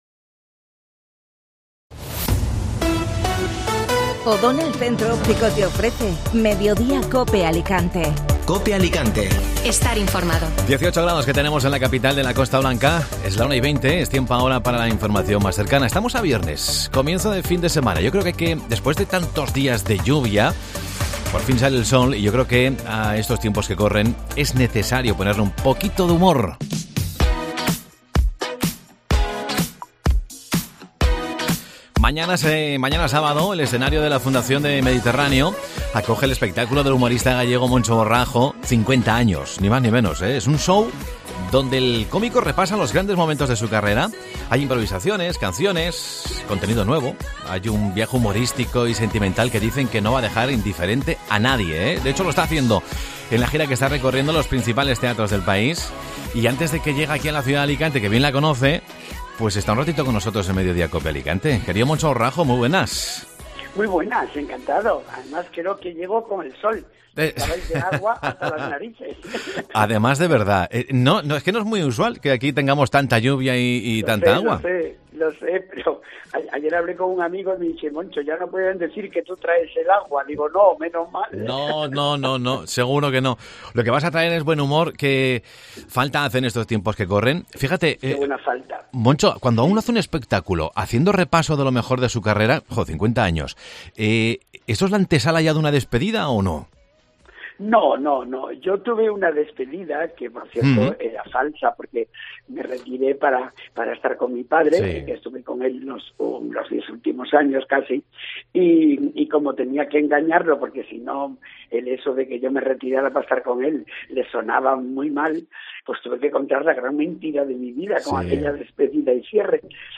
AUDIO: El cómico gallego actuará este sábado en Fundación Mediterráneo. Escucha la entrevista en Mediodía COPE Alicante.